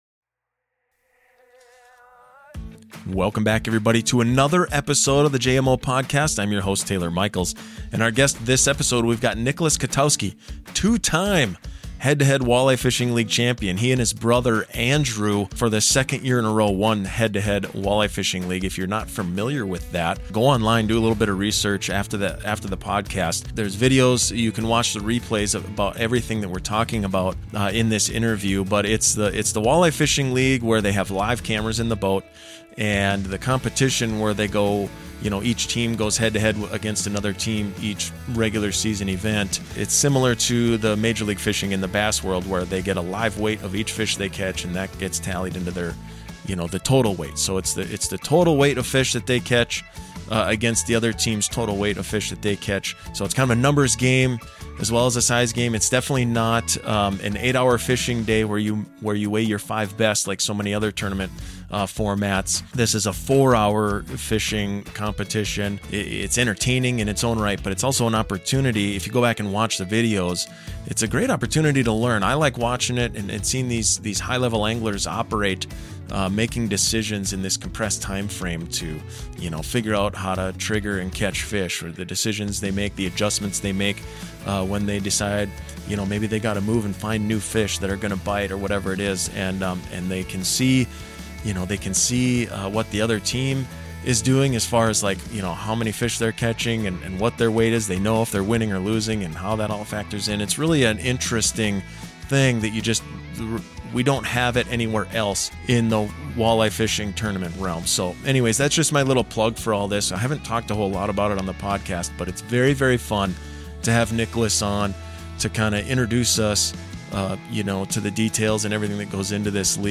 In this interview we get …